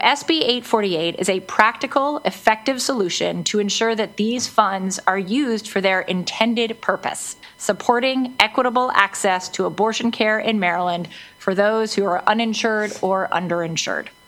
Maryland Senate Bill 848 had a hearing before the Senate Finance Committee late last week. The bill moves money that has been essentially sitting in an account of unspent insurance funds to the Maryland Department of Health to help fund abortions.
State Comptroller Brooke Lierman testified that the bill requires no additional funding…